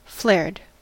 Ääntäminen
Ääntäminen US Tuntematon aksentti: IPA : /fleəɹɪd/ Haettu sana löytyi näillä lähdekielillä: englanti Käännöksiä ei löytynyt valitulle kohdekielelle.